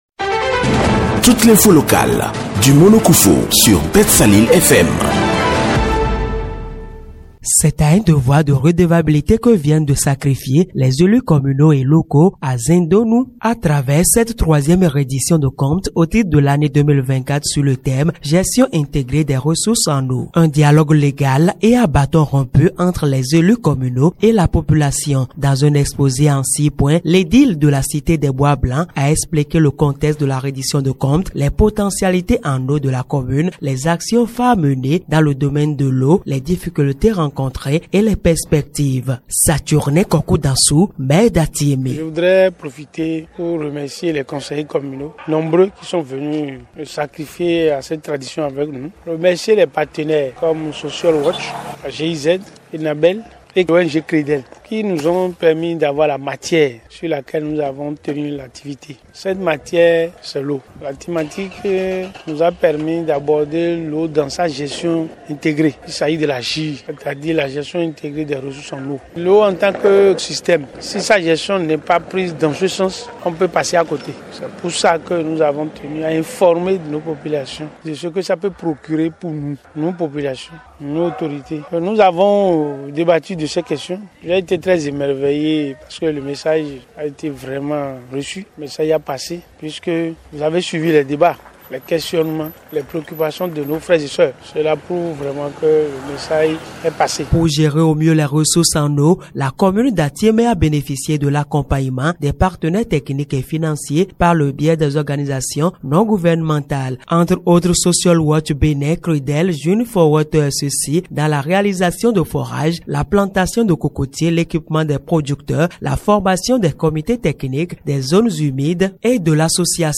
L’activité a eu pour cadre la place publique de Zindonou dans l’arrondissement de Dédékpoè. “Gestion intégrée des ressources en eau”, c’est le thème autour duquel s’est déroulée cette séance de reddition de comptes.
Voici son reportage